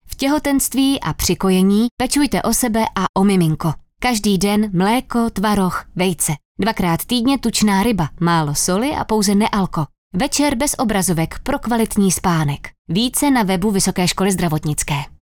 Letáky budou distribuovány do ambulantních gynekologicko-porodnických ordinací a audiospot bude vysílaný Českým rozhlasem v rámci Světového týdne kojení, který probíhá od 1. srpna do 8. srpna 2025.
Spot 2